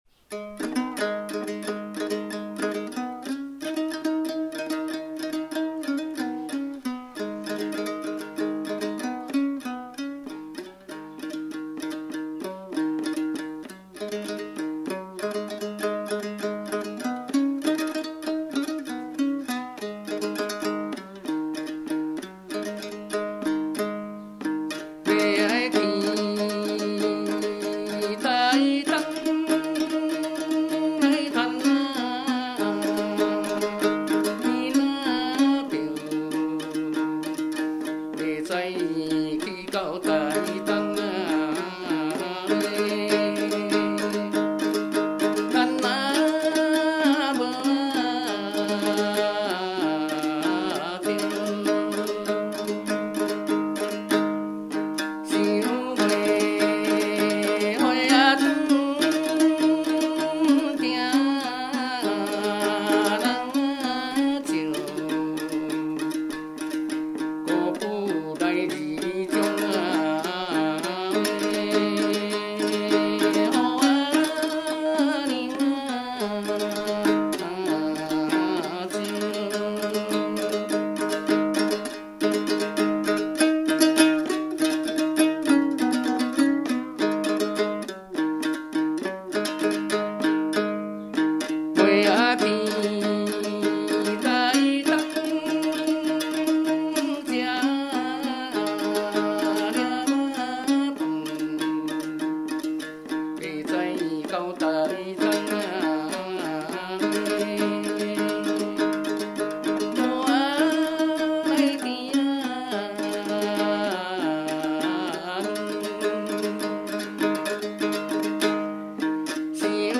18首原汁原味的素人歌声，在粗哑中满怀真性情，那是上一代的回忆，这一代的情感，下一代的宝藏！
独唱